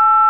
Tone8
TONE8.WAV